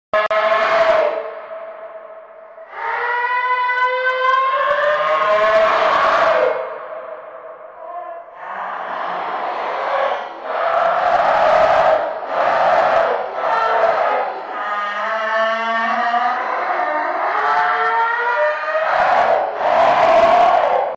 de in scream ID ROBLOX sound effects free download